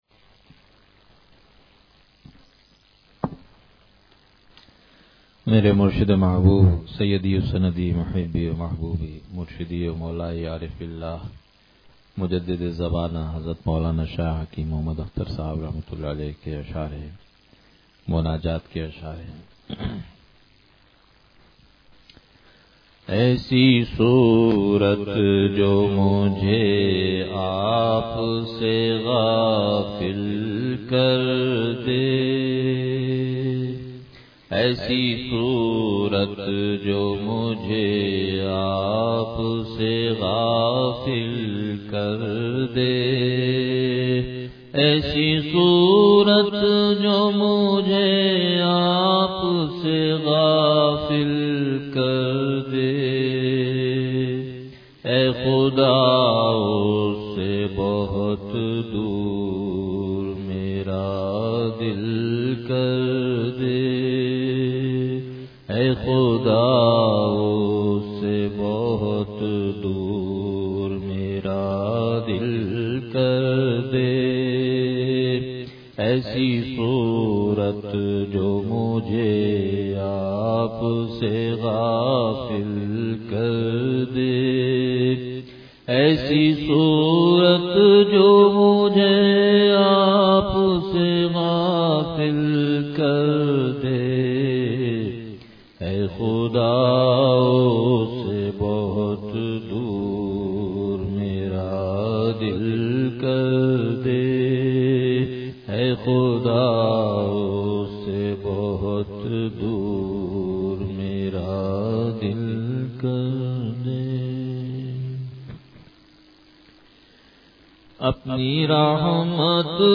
مناجات – ایسی صورت جو مجھے آپ سے غافل کر دے – الہامات ربانی